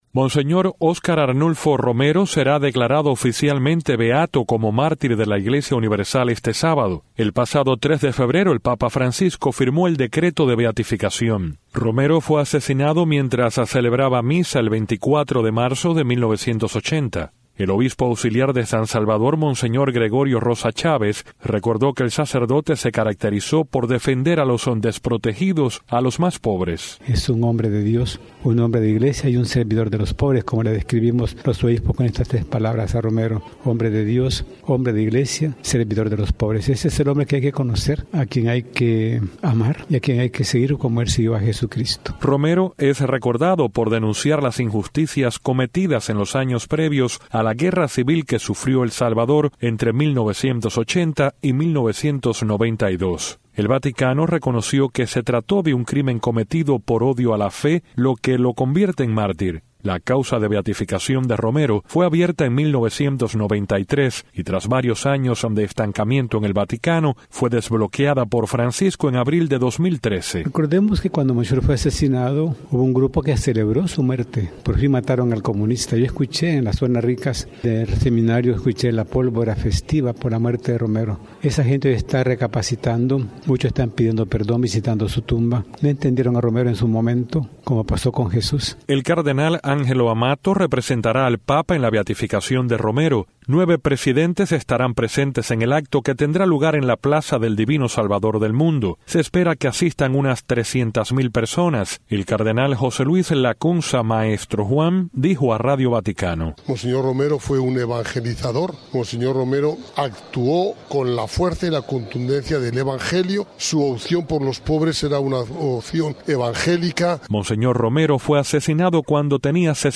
Noticias de Radio Martí